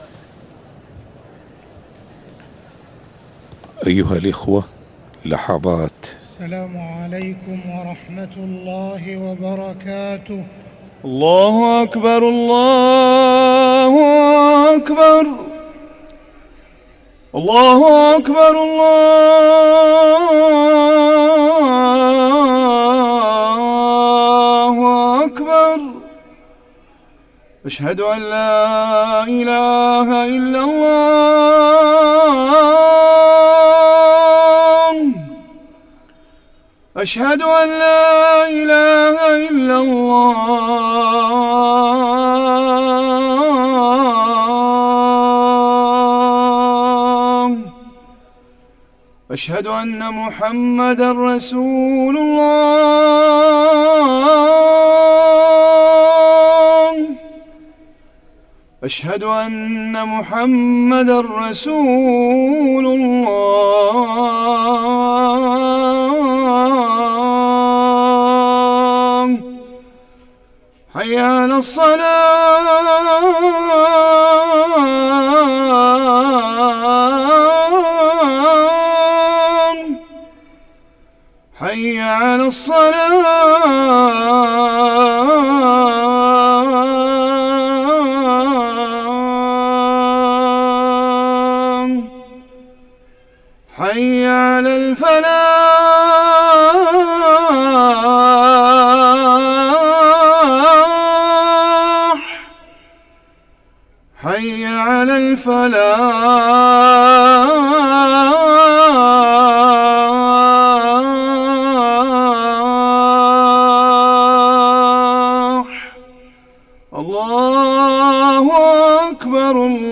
أذان الجمعة الثاني للمؤذن